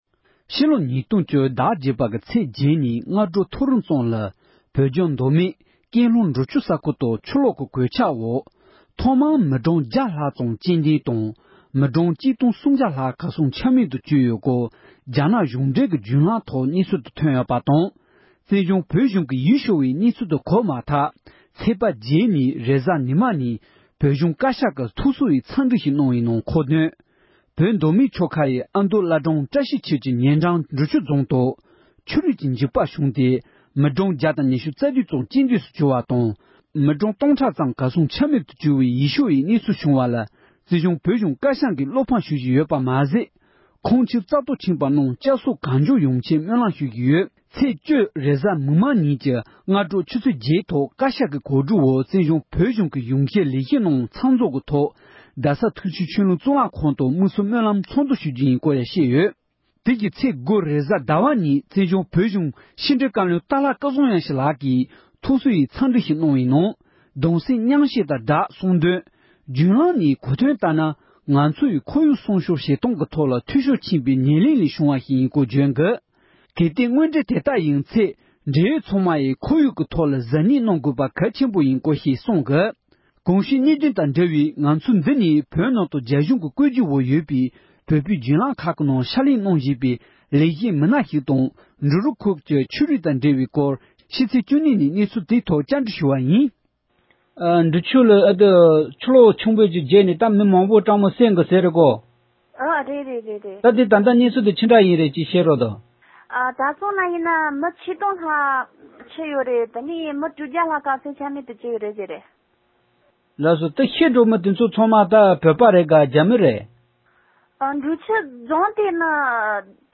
སྒྲ་ལྡན་གསར་འགྱུར།
རང་དབང་གི་གླེང་མོལ།